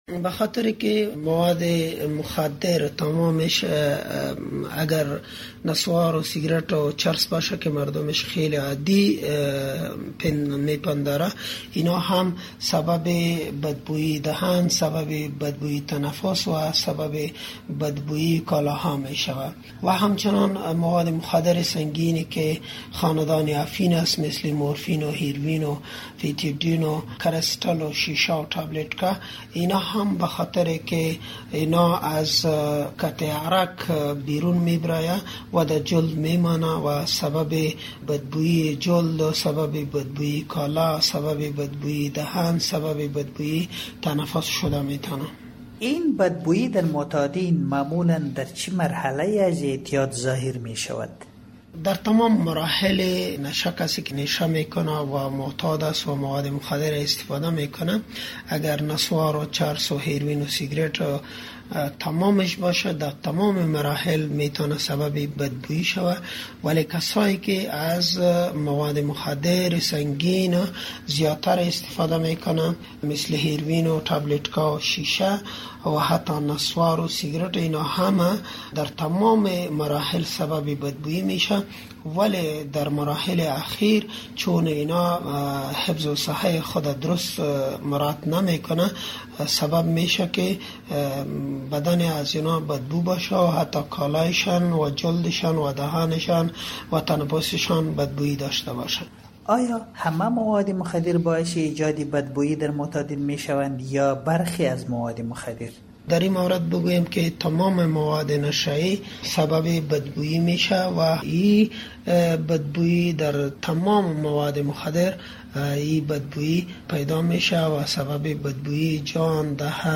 این که بوی بد یا همان گنده بغل در معتادان مواد مخدر در کدام مرحله اعتیاد به میان میآید، کدام نوع مواد مخدر بیشتر این مشکل را به وجود میآورد و چگونه میشود از انتشار این مشکل در میان معتادان جلوگیری کرد؟ ، این پرسشها را همکار ما در مصاحبه